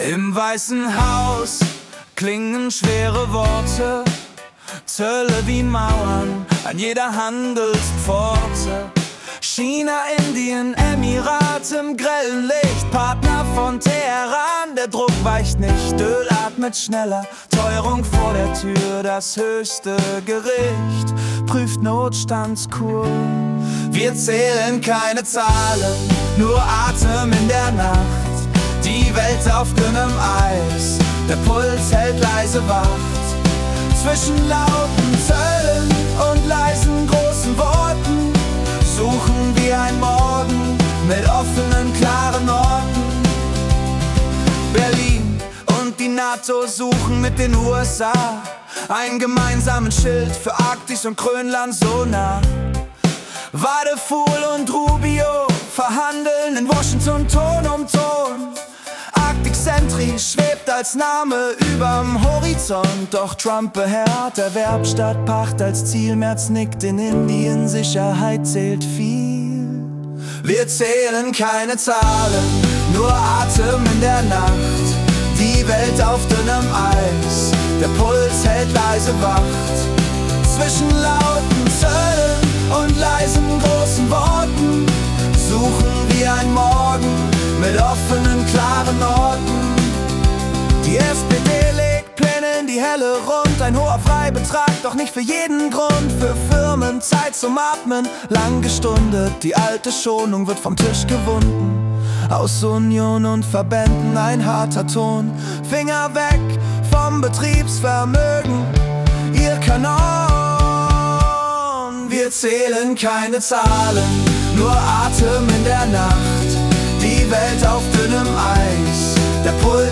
Die Nachrichten vom 14. Januar 2026 als Singer-Songwriter-Song interpretiert.